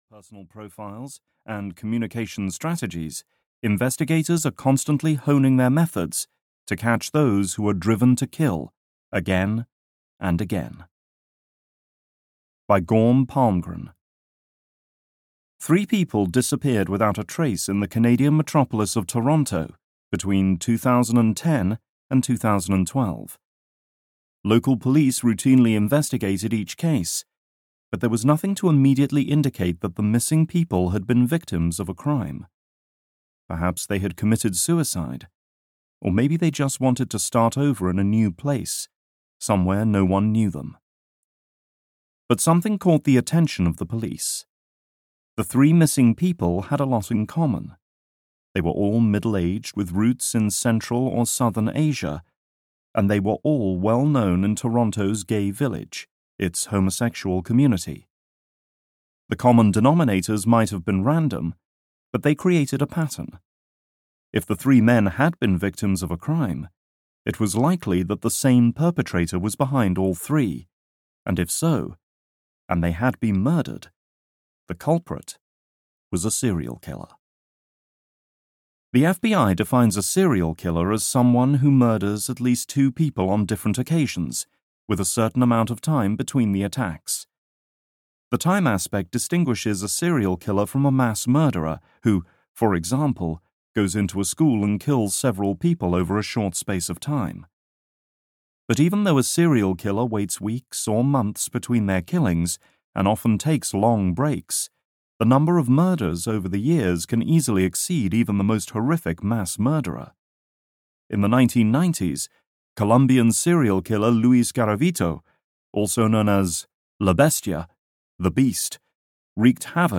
Ice Cold Killers - The Rituals of Murderers (EN) audiokniha
Ukázka z knihy